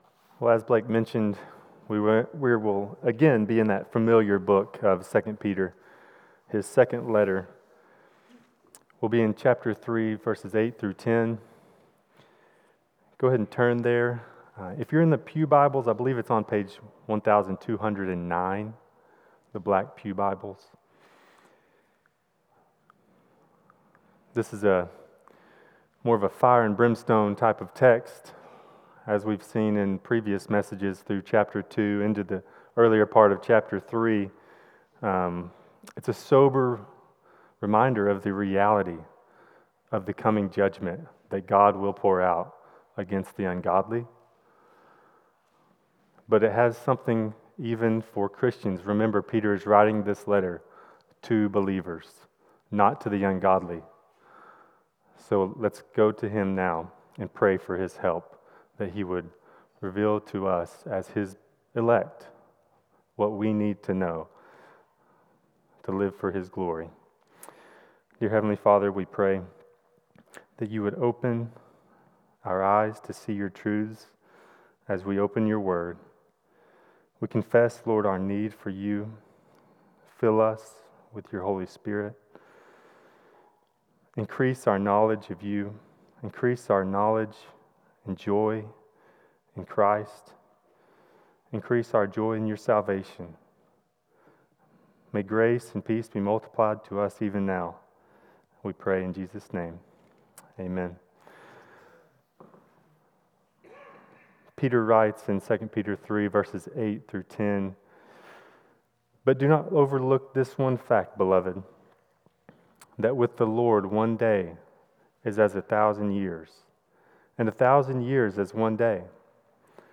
CCBC Sermons 2 Peter 3:8-10 Dec 02 2024 | 00:40:14 1x 00:00 / 00:40:14 Subscribe Share Apple Podcasts Spotify Overcast RSS Feed Share Link Embed